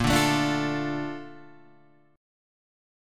A# Major 7th